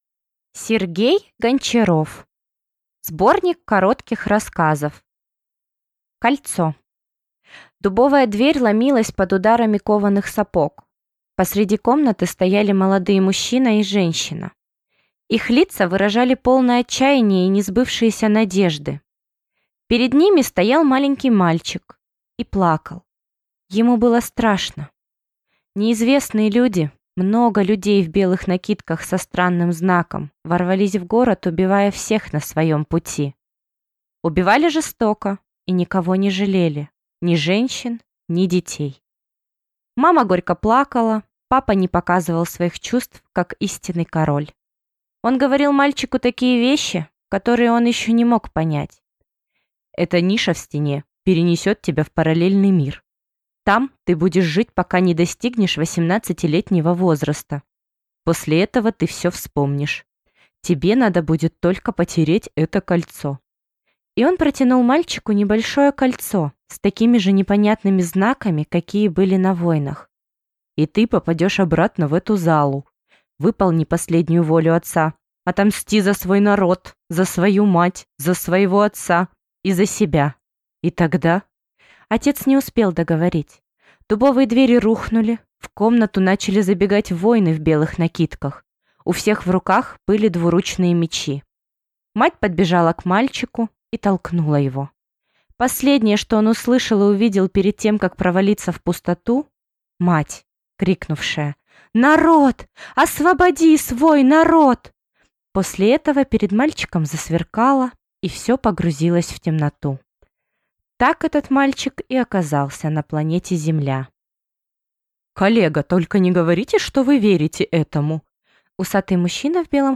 Аудиокнига Сборник коротких рассказов | Библиотека аудиокниг